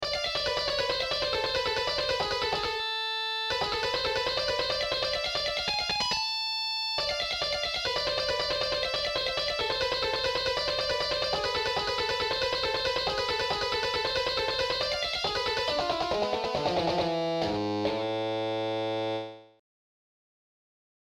Heavy Metal Guitar School > Metal Riffs - Neoclassic Style
Metal+Riffs+-+Neoclassic+Style.mp3